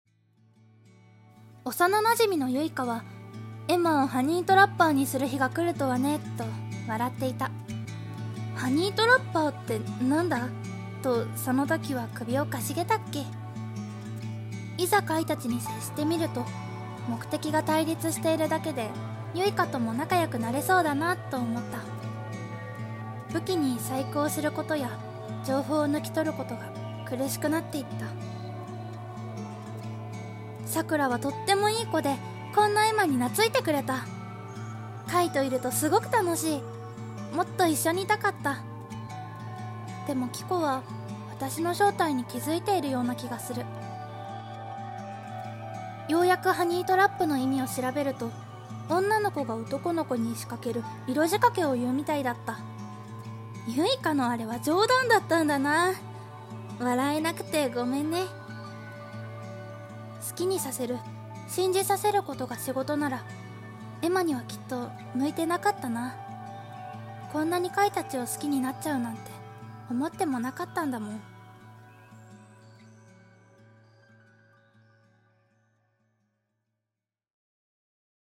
ソロ声劇